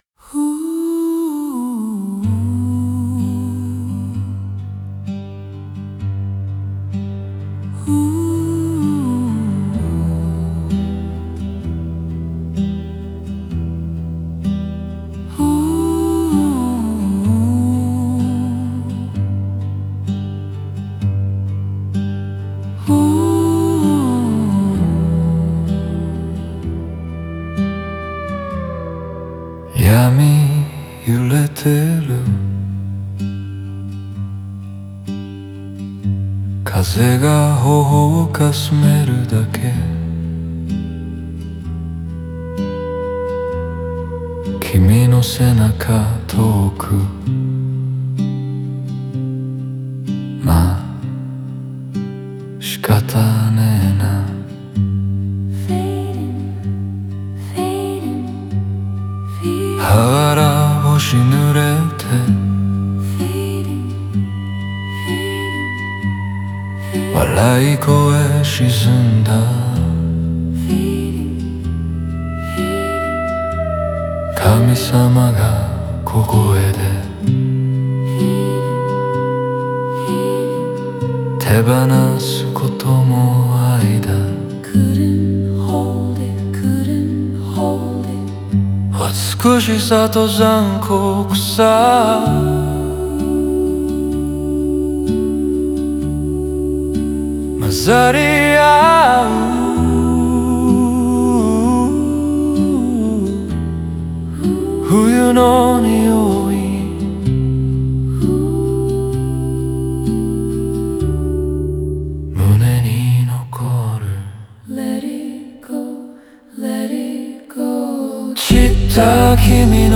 この歌詞は、孤独な心の声をモノローグ的に描き、無音や余白を意識した構成で情景を映像的に浮かび上がらせています。